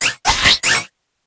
Cri de Verpom dans Pokémon HOME.